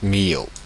Millau (French pronunciation: [mijo]
Fr-Millau.oga.mp3